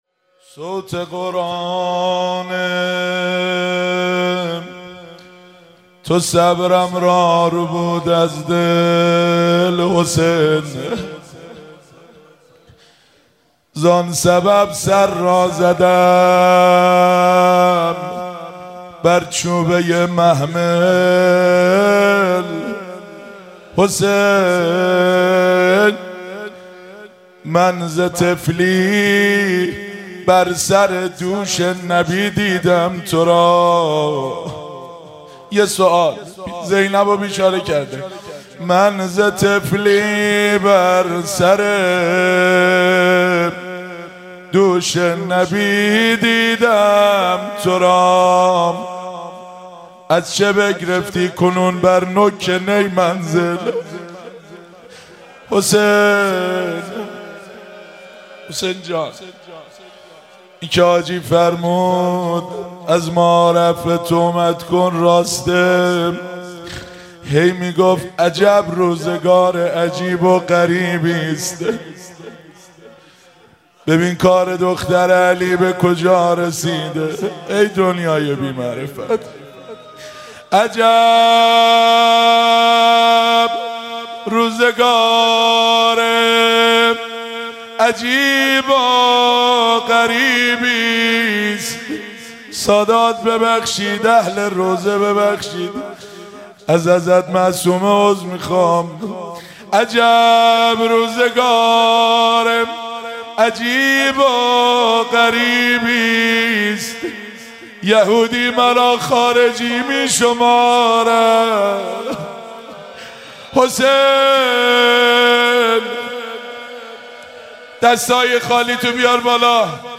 مراسم مناجات خوانی شب بیست و دوم ماه رمضان 1444